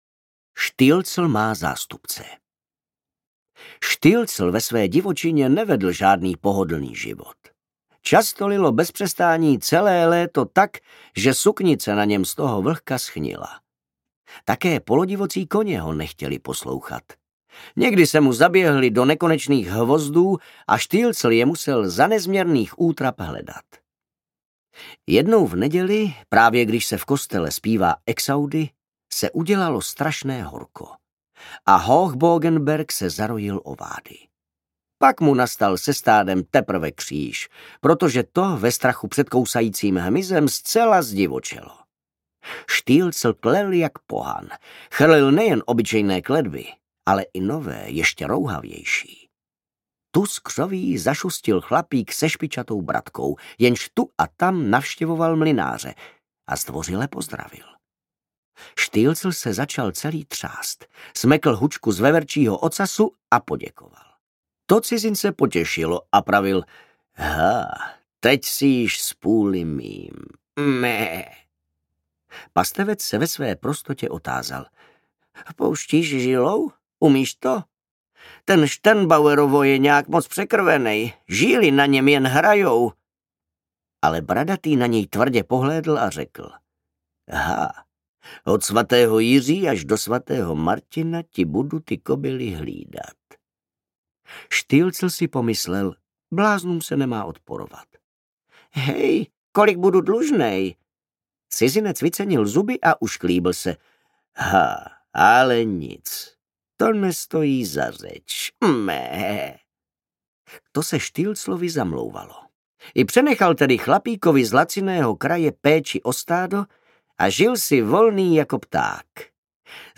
Stilzel, skřet šumavský audiokniha
Ukázka z knihy
Vyrobilo studio Soundguru.